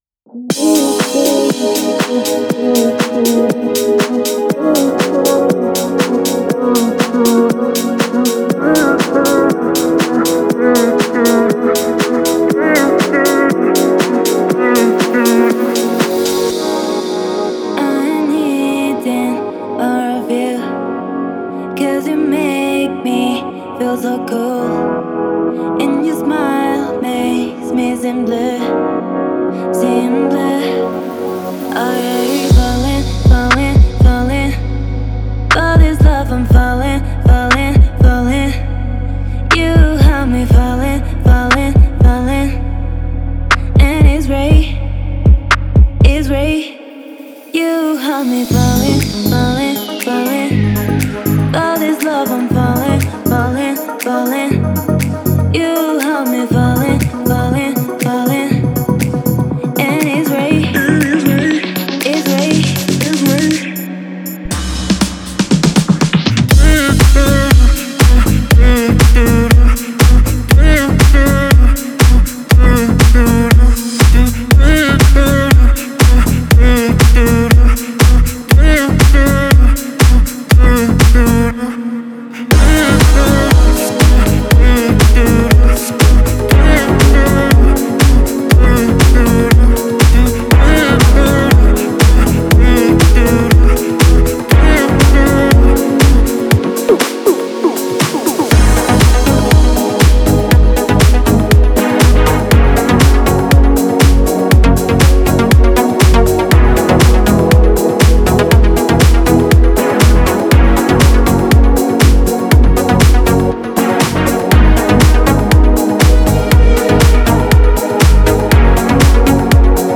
это захватывающая песня в жанре R&B